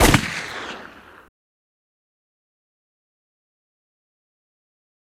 Sample God - Gun shot.wav